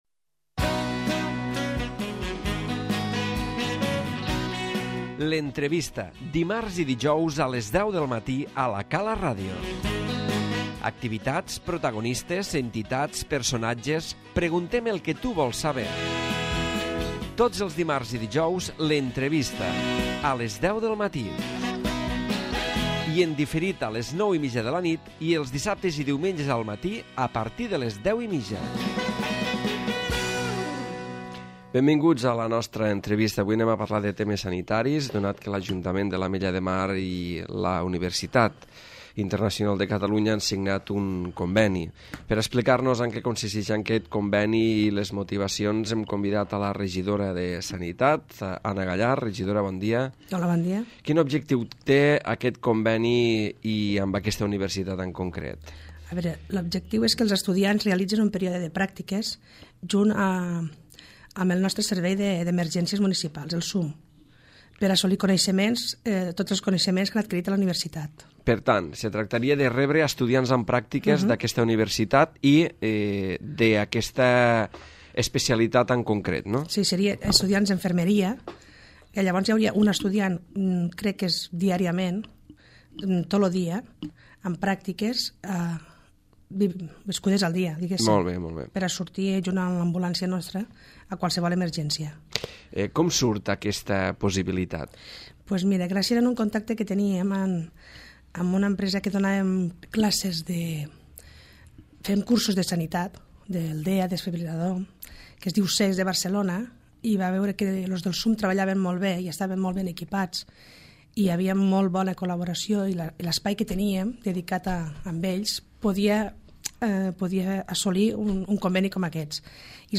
L'Entrevista
La regidora de Sanitat de l'Ajuntament de l'Ametlla de Mar Anna Gallart ha estat avui la invitada a l'entrevista on ha explicat que el consistori han signat el conveni perquè els estudiants d’infermeria puguin fer la seva formació pràctica al Servei